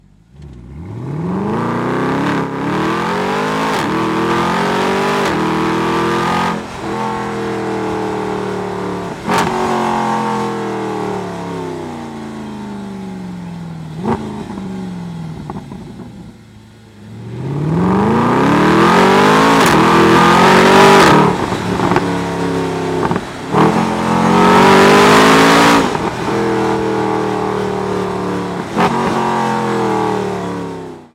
Hab vom SLS mit 6.3 Liter nen Soundfile von der letzten IAA daheim.
sonor und "mechanisch".
Hier mal das Soundfile, welches mir der SLS per Bluethooth direkt aufs Handy geschickt hat.
SLS AMG Sound Sample
SLS_AMG_Sound.mp3